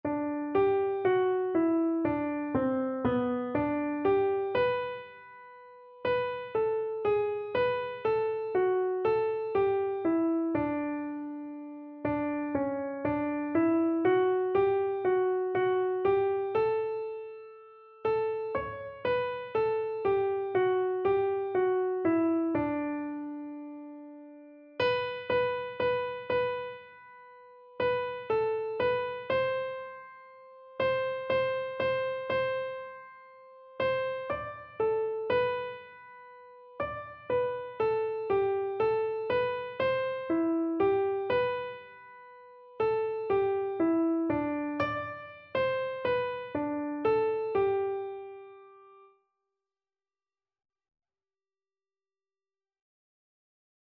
Free Sheet music for Keyboard (Melody and Chords)
6/4 (View more 6/4 Music)
Keyboard  (View more Easy Keyboard Music)
Classical (View more Classical Keyboard Music)